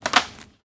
R - Foley 152.wav